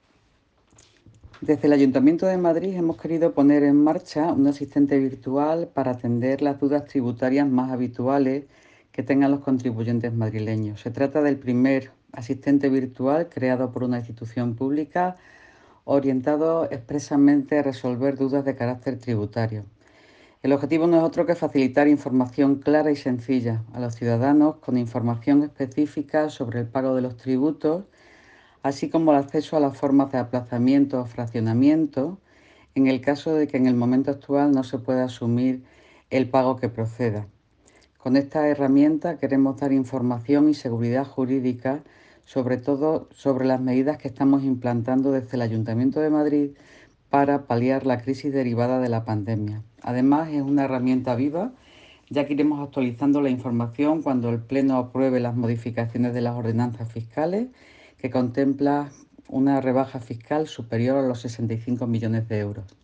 Nueva ventana:Declaraciones de Engracia Hidalgo, delegada del Área de Gobierno de Hacienda y Personal